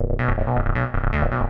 hous-tec / 160bpm / bass
rubber-6.mp3